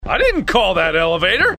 m_elevator.mp3